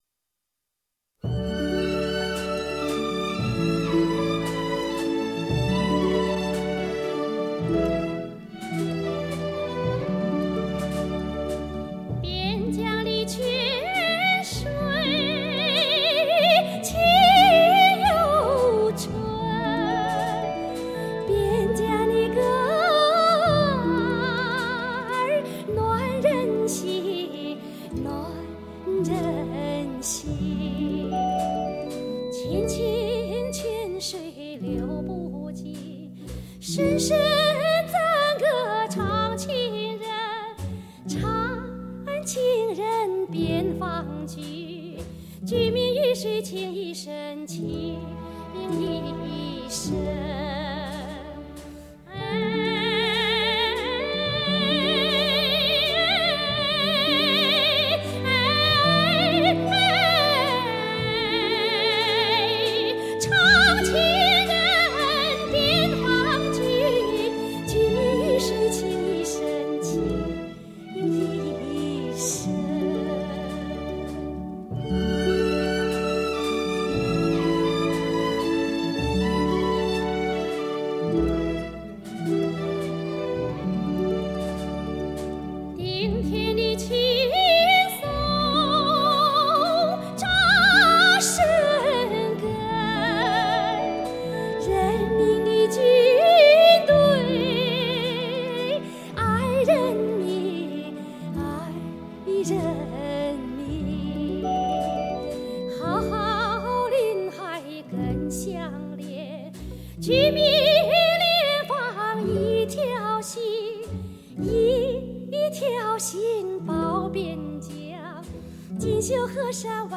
其演唱融合中国戏曲行腔和西洋声乐艺术，行腔婉转流畅，吐字清晰，感情纯真炽热，独树一代歌风。